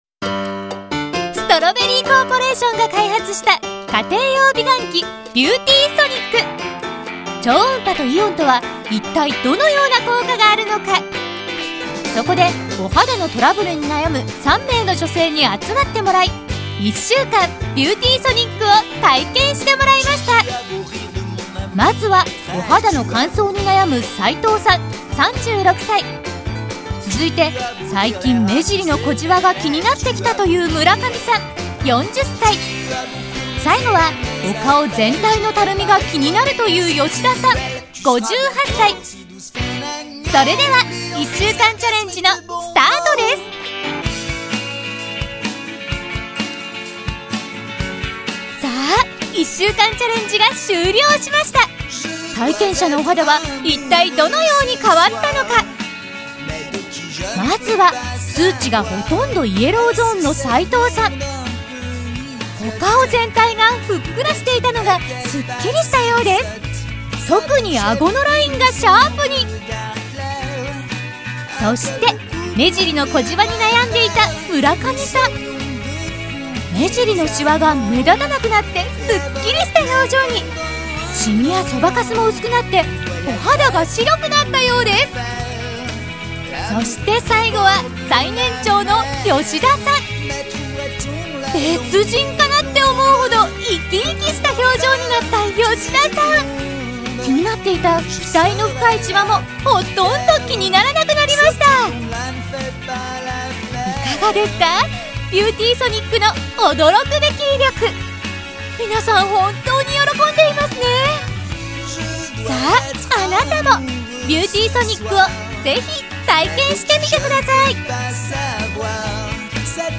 新しいボイスサンプルです
テレショップ（テンション高）